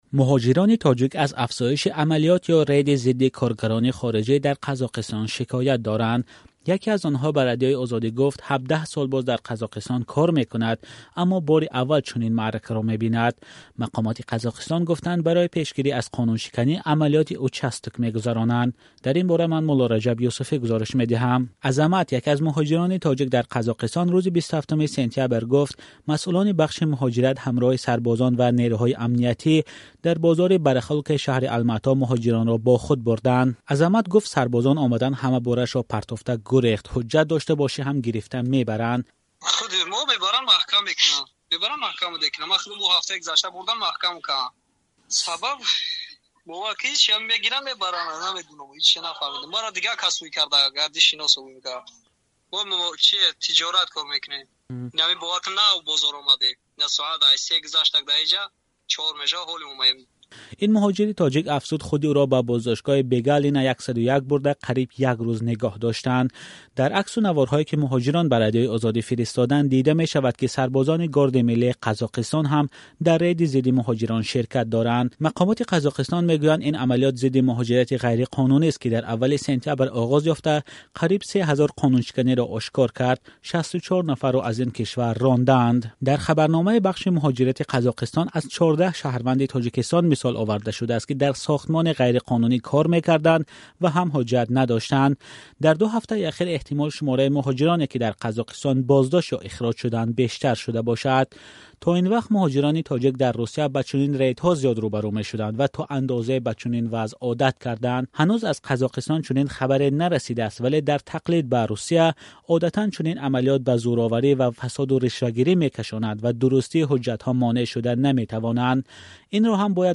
Гузоришҳои радиоӣ